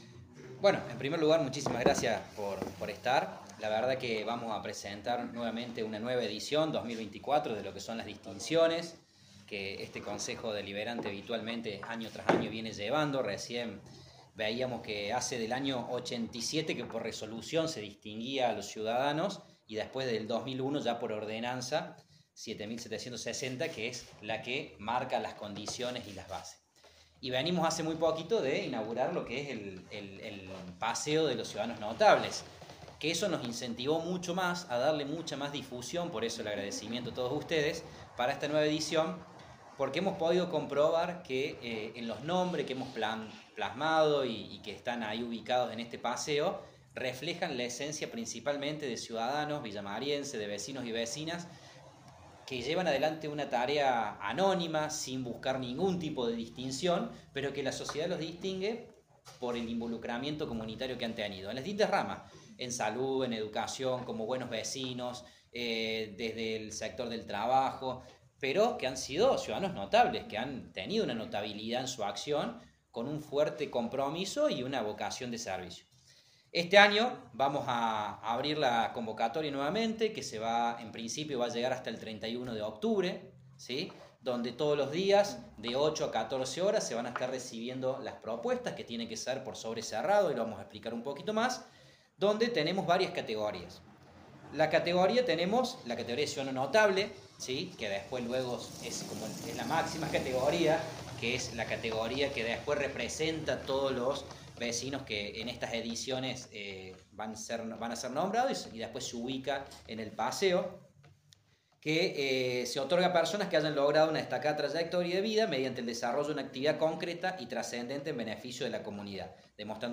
Con una conferencia de prensa, el presidente del Concejo Deliberante, Juan Pablo Inglese, informó que se encuentra habilitada la posibilidad de postular propuestas para el Ciudadano Destacado de Villa María en este 2024.